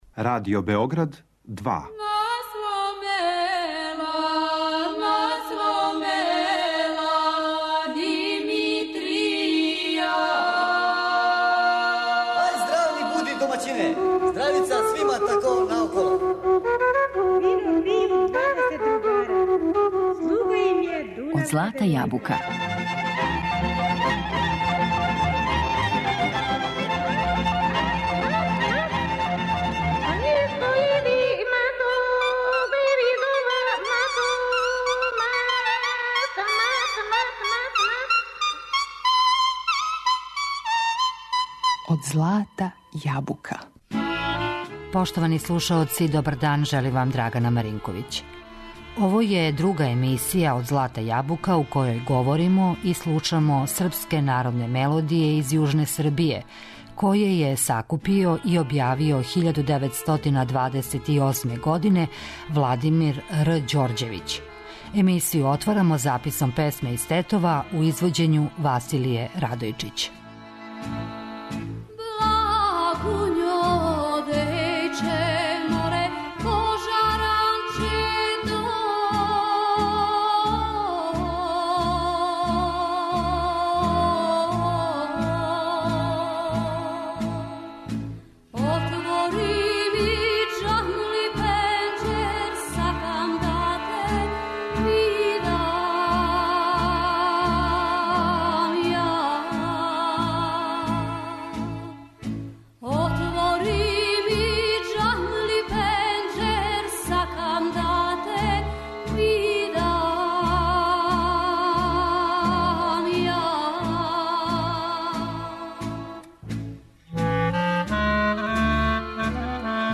Слушамо песме у извођењу Василије Радојчић